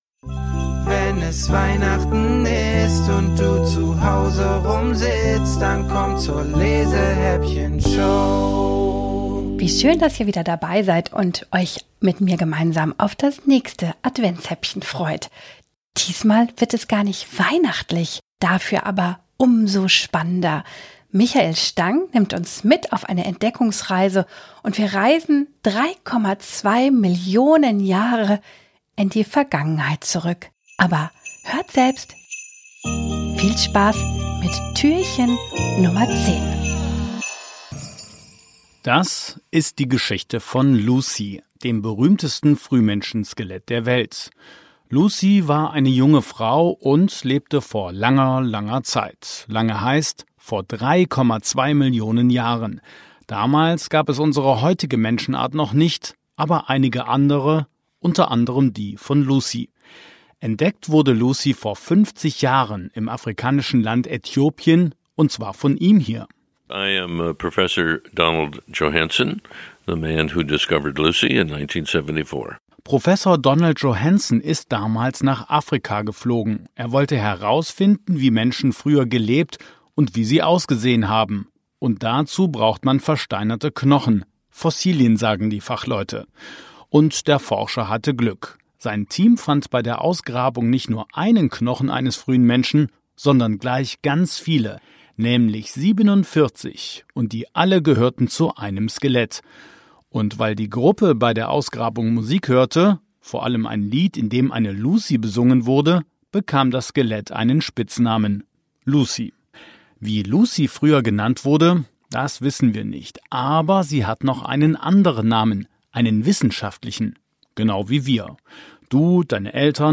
Autorinnen und Autoren höchstpersönlich vertont wurden!